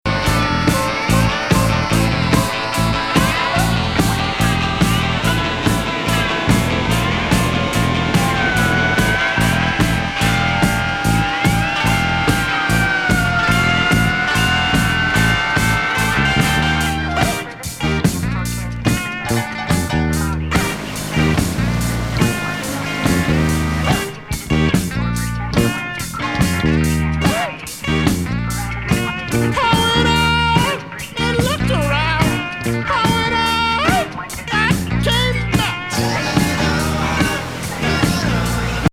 クリーブランドの電子アヴァンギャルド・ポスト・パンク・グループの78年セカンド。 かなりグダグダに展開する奇天烈レゲー「
ノイズ～実験的電子音、テープコラージュ入り混じる混沌迷作。